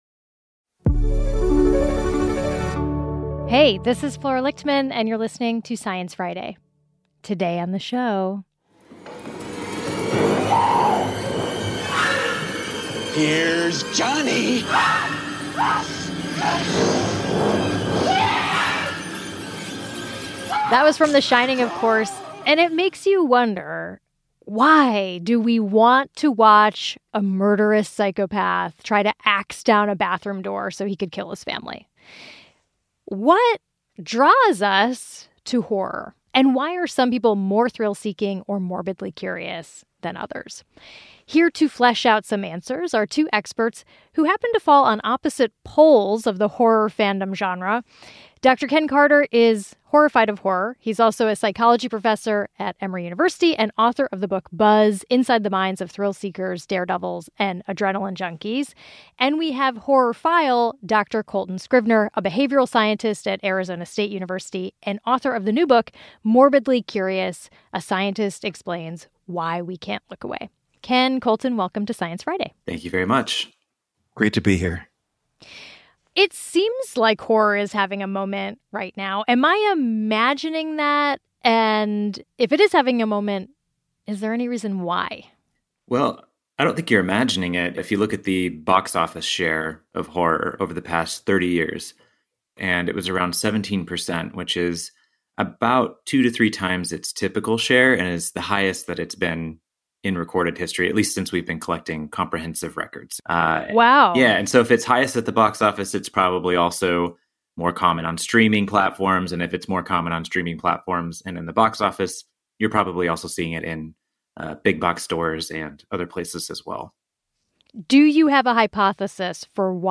Why do we find joy in the scary and the macabre? Two psychology experts weigh in on humans' affinity for horror and desire to get spooked.